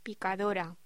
Locución: Picadora
voz